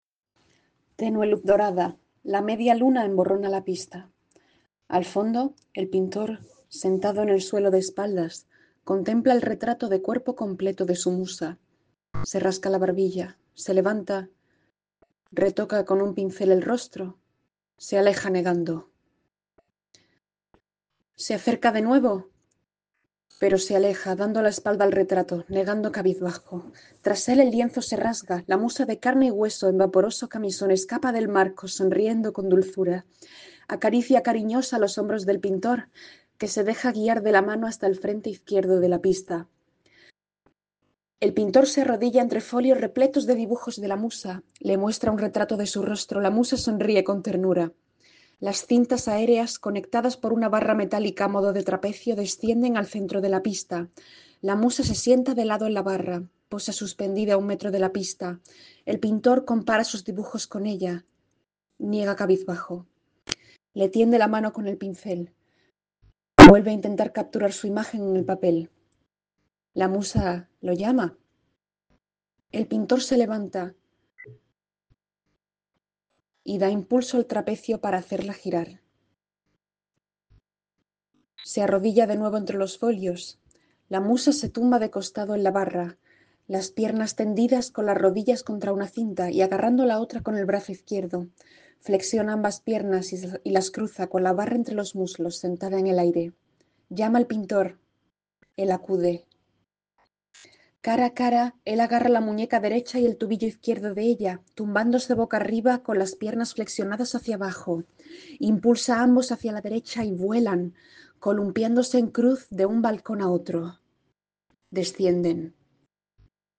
se escuchaba audiodescribir por el receptor en uno de los números de acrobacias.
audiodescripcion-de-uno-de-los-numeros-mp3